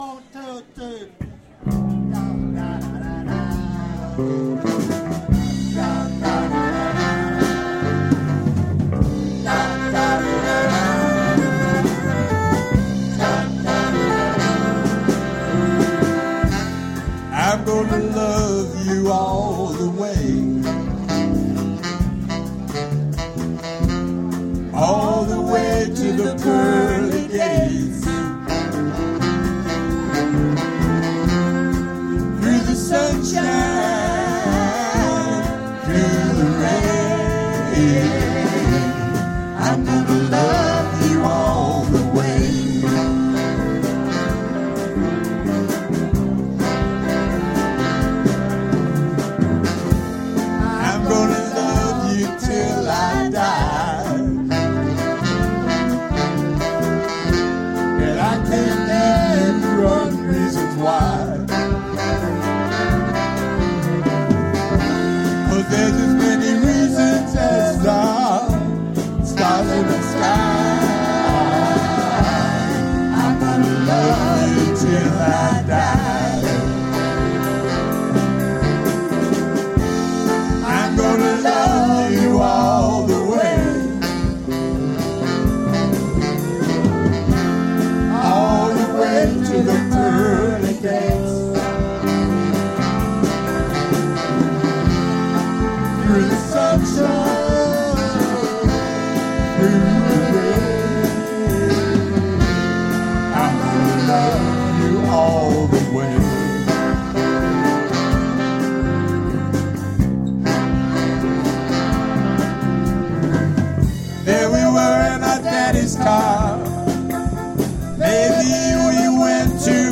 - Memorial concert at Bird's -
These links are to the recording of the show from my Tascam DR-07 that was mounted right in front of the monitor.